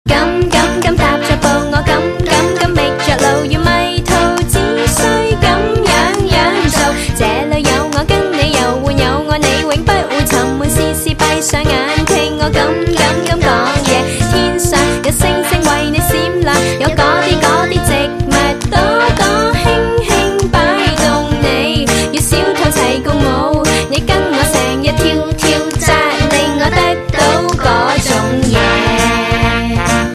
M4R铃声, MP3铃声, 华语歌曲 74 首发日期：2018-05-13 15:23 星期日